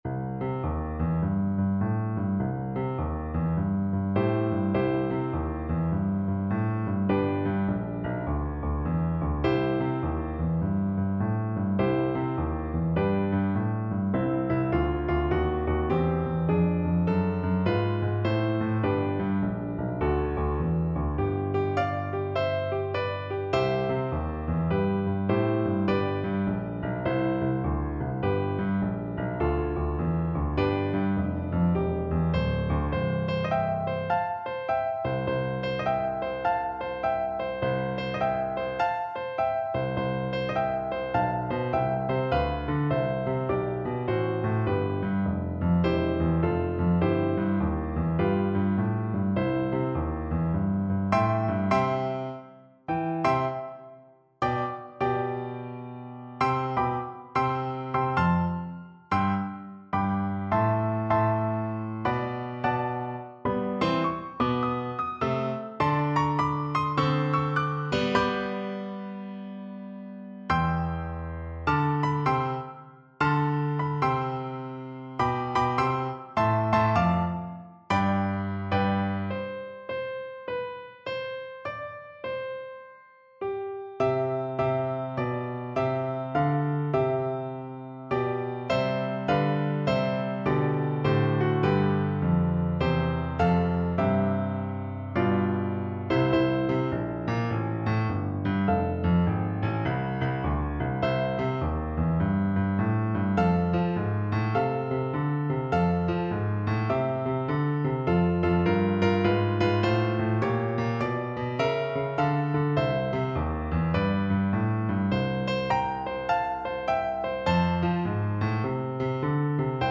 4/4 (View more 4/4 Music)
With a swing! =c.140
Classical (View more Classical Cello Music)